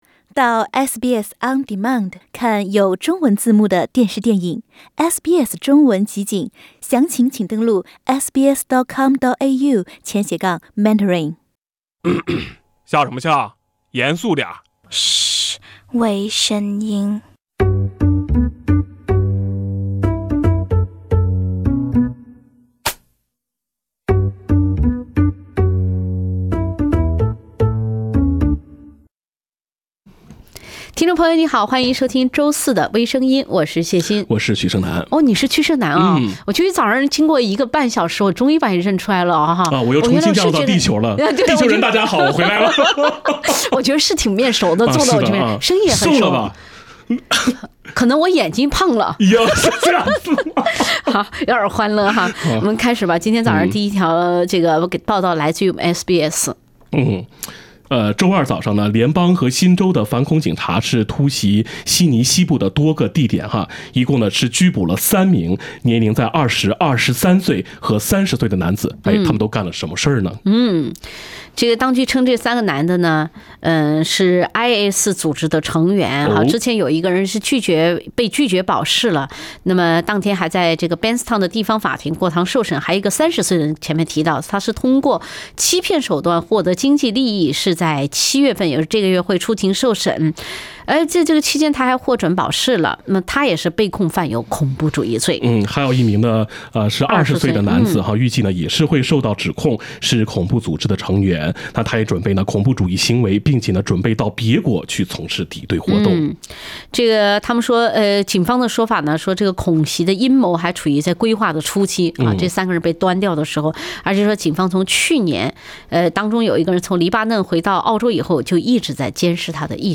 另类轻松的播报方式，深入浅出的辛辣点评；包罗万象的最新资讯；倾听全球微声音。